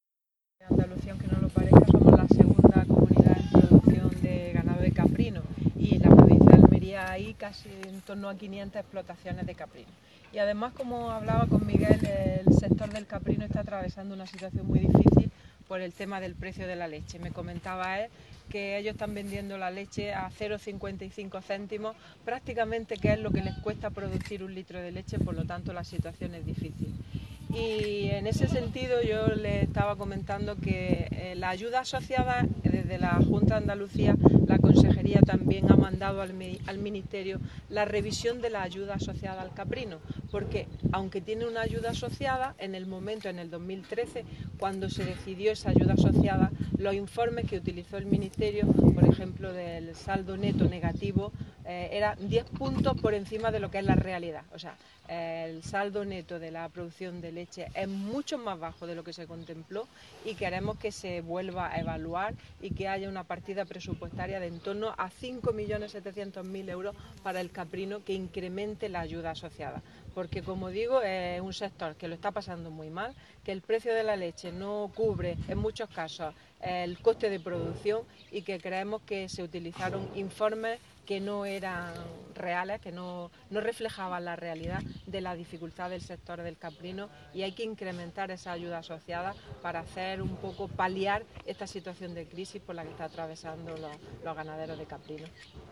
Declaraciones de la consejera sobre ayudas asociada de la PAC para caprino